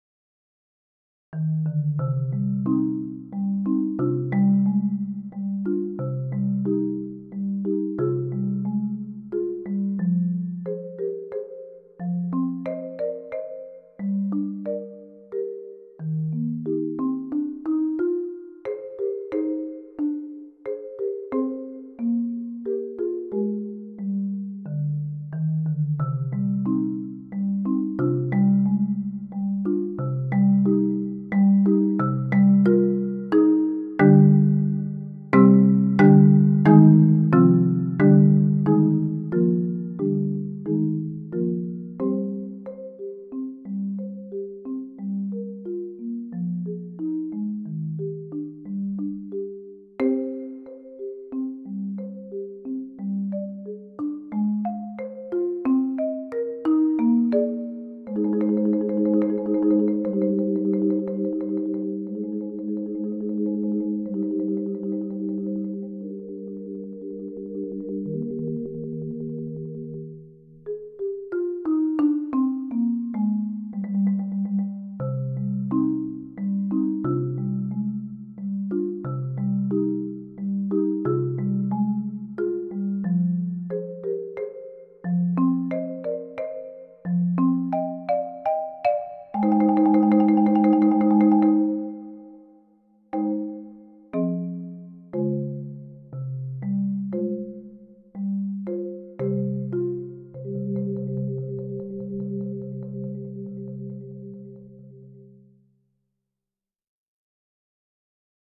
Genre: Solo 4-Mallet Marimba
Marimba [4-octave]